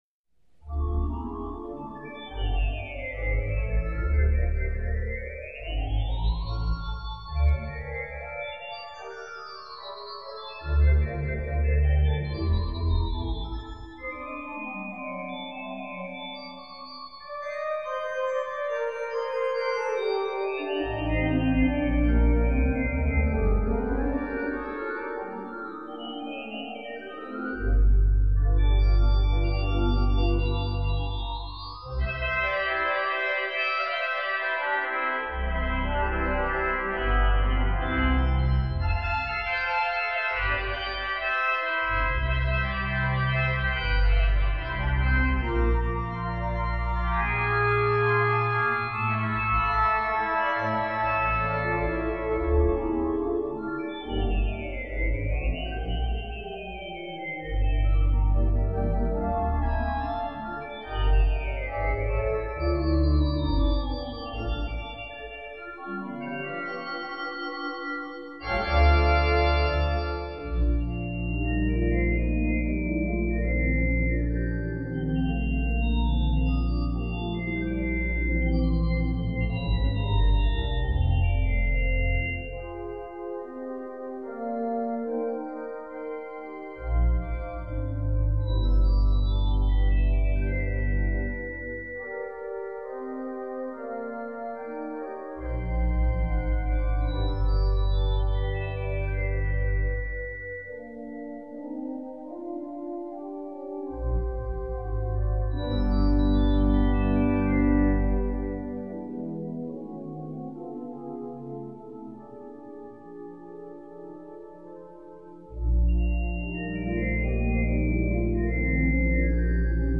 This console enables the organist to play the Dominican Sanctuaire Pipe Organ (organ chambers appear above console in left picture), as well as the 71 digital ranks emanating from the transept gallery.
• Live acoustics
Four Manual Lighted Drawknob Console